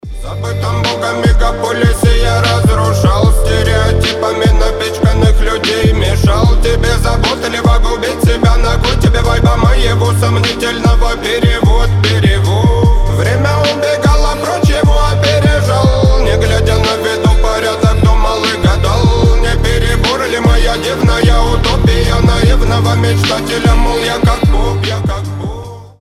Рэп рингтоны
Хип-хоп